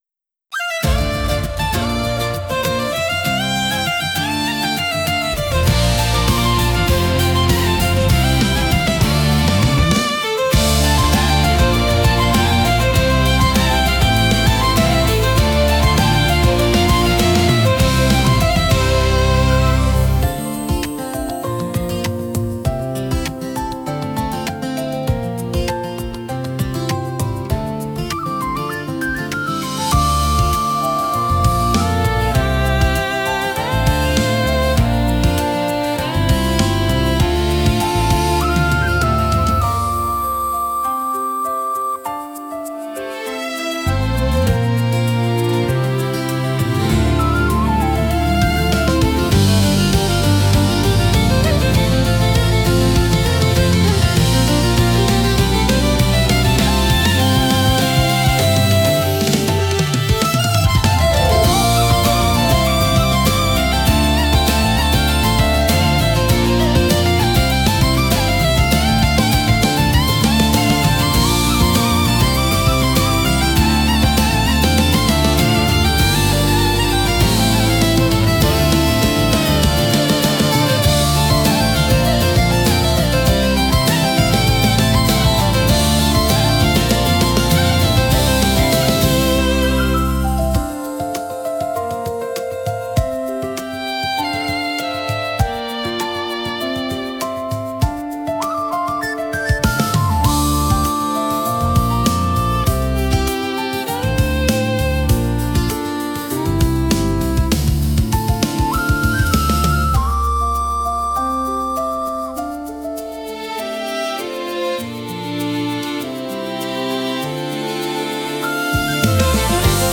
BGM / Instrumental